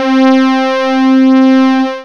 OBIE STRING.wav